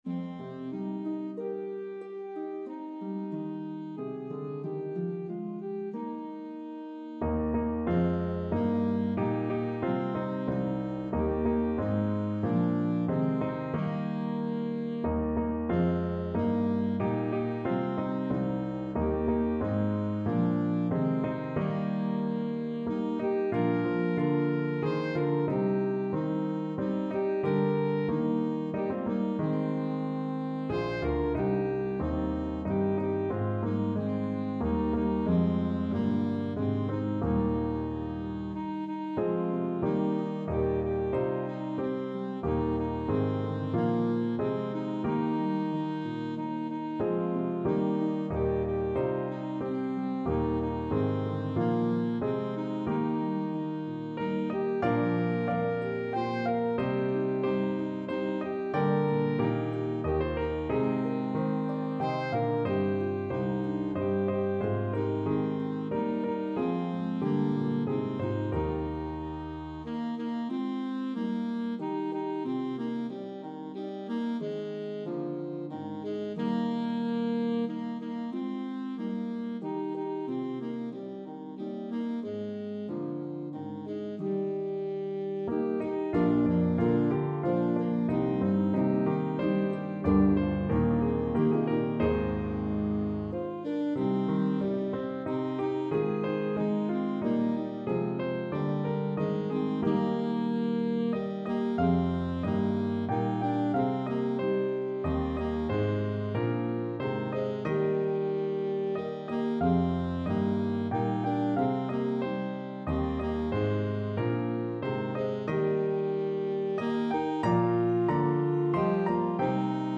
A meditative trio arrangement of the 1844 hymn tune
is a pentatonic hymn tune